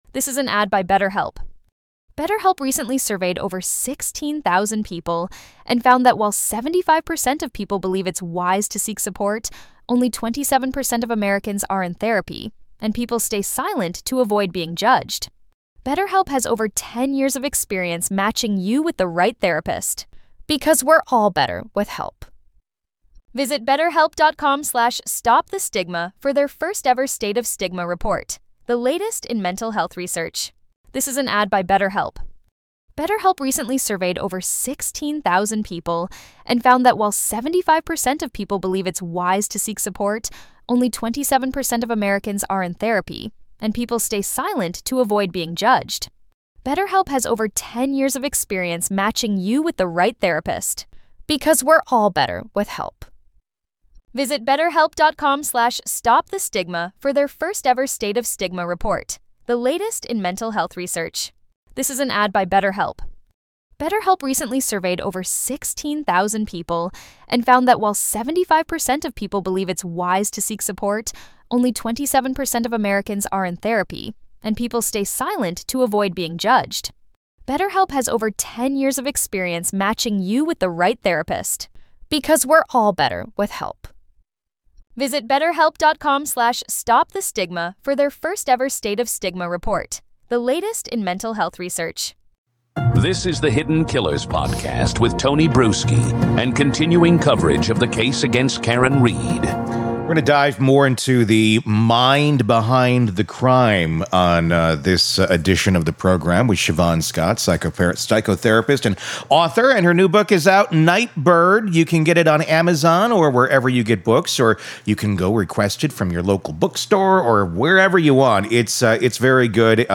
In this gripping conversation with psychotherapist